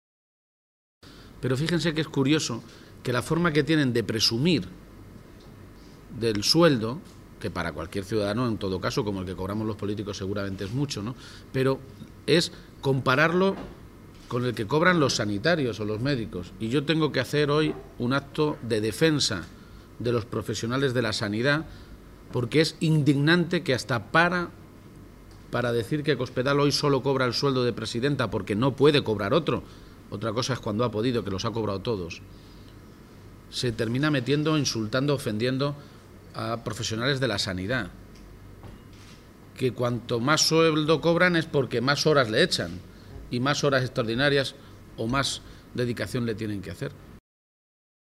García-Page, que compartió un desayuno informativo con los medios de comunicación en Cuenca, insistió una vez más en la necesidad de que la presidenta de Castilla-La Mancha, María Dolores de Cospedal, comparezca en las Cortes regionales tras las últimas informaciones desprendidas del denominado caso Bárcenas.